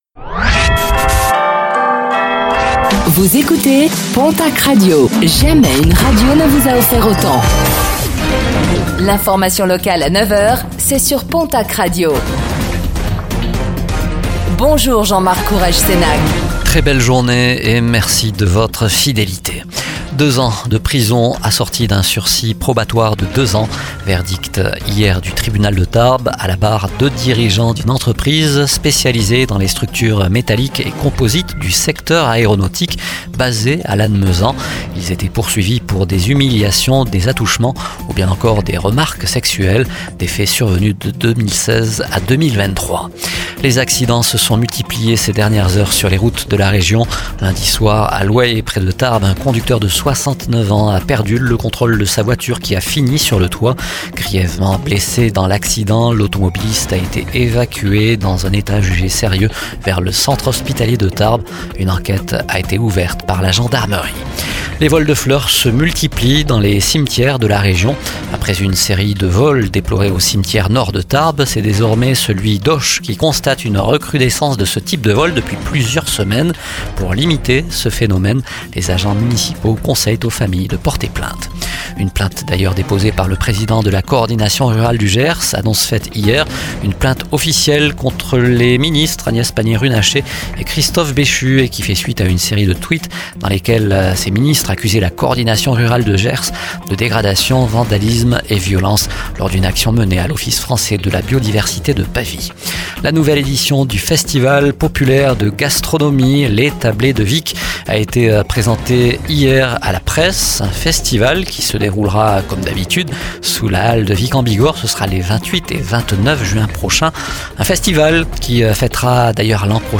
Réécoutez le flash d'information locale de ce mercredi 29 mai 2024